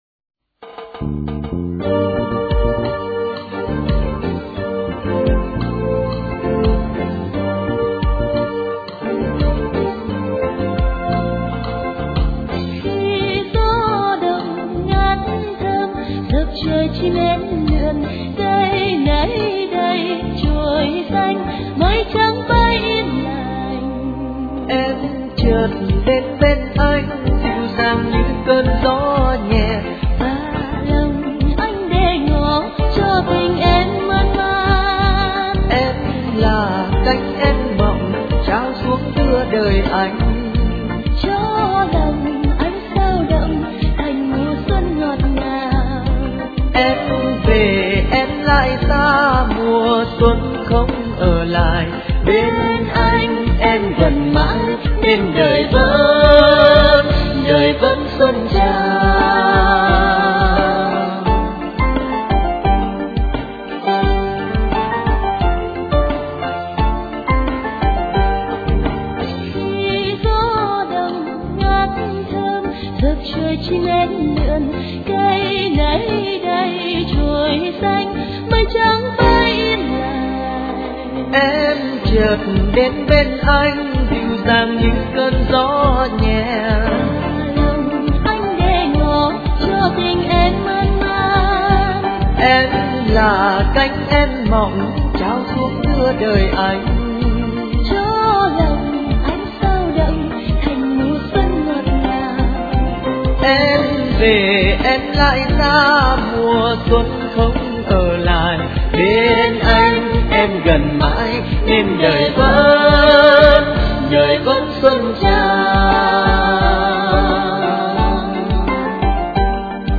* Thể loại: Xuân